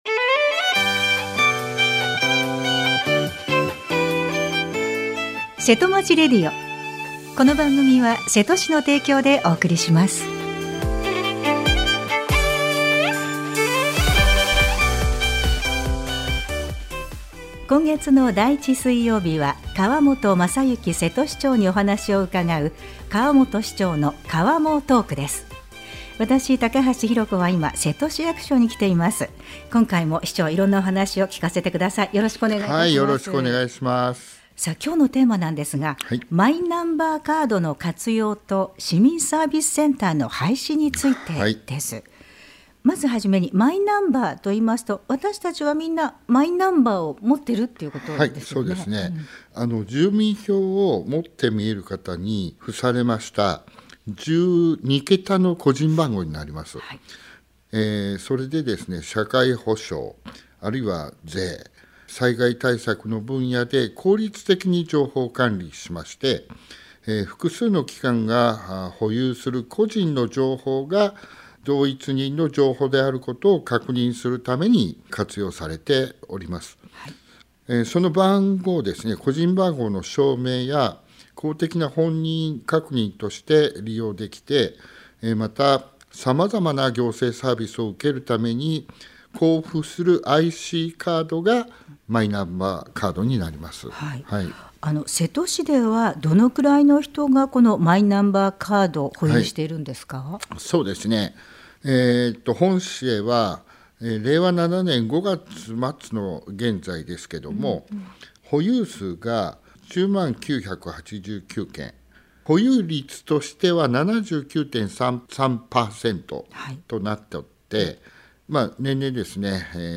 川本雅之瀬戸市長にお話を伺いました。